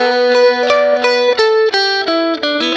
Track 02 - Guitar Lick 09.wav